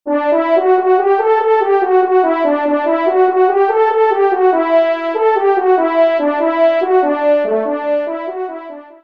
20 sonneries pour Cors et Trompes de chasse